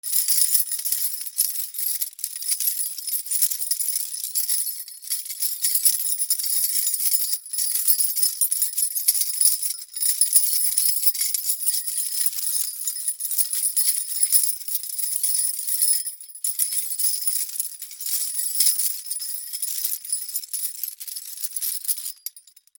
鍵束から鍵を探す 02
/ K｜フォーリー(開閉) / K35 ｜鍵(カギ)